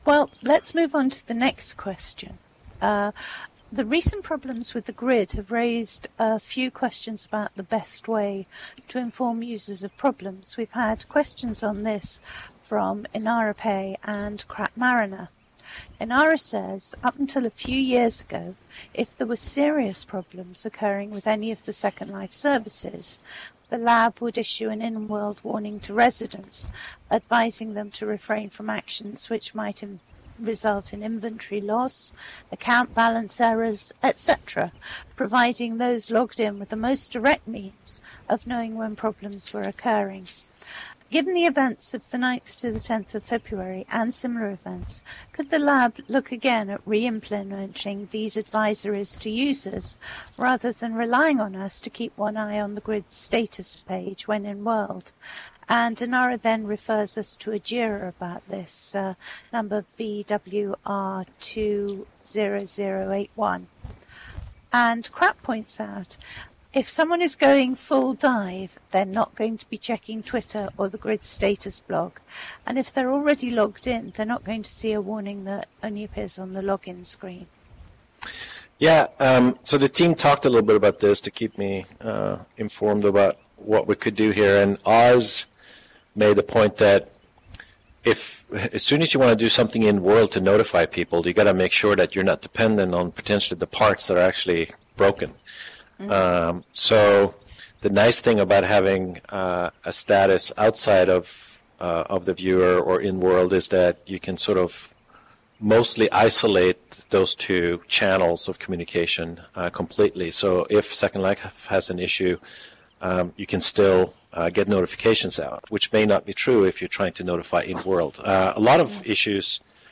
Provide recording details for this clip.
Each question and response is supplied with an accompanying audio extract.